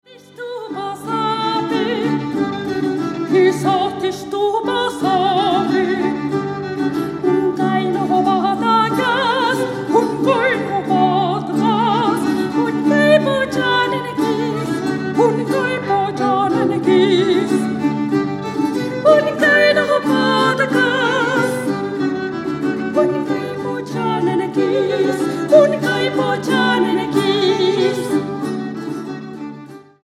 Aria
Dúo